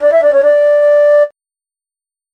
Alarm Flute.mp3